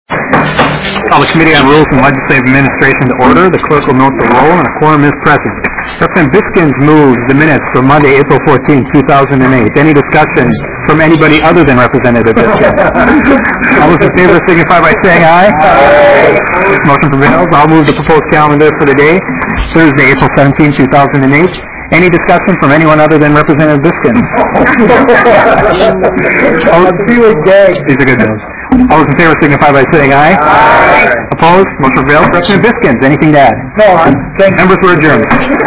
Representative Tony Sertich, Chair, called the meeting to order at 4:50 p.m., on Wednesday April 16, 2008 in Room 118 State Capitol.